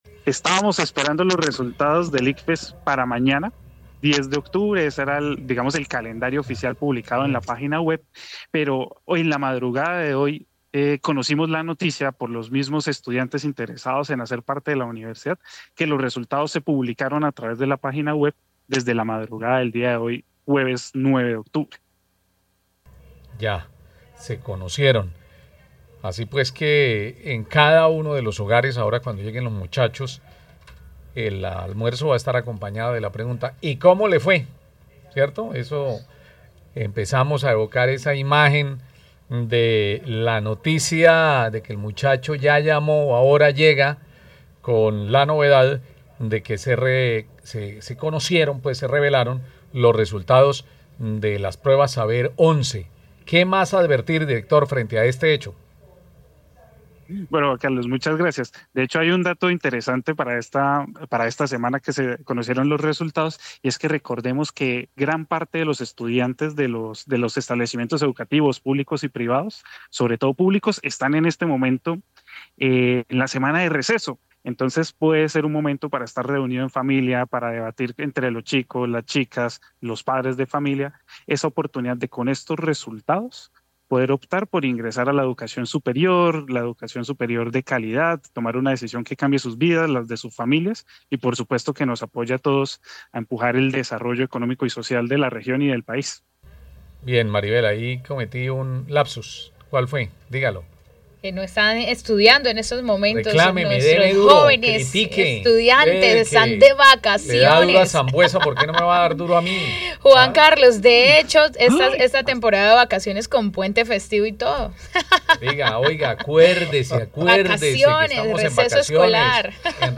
durante una entrevista con Caracol Radio.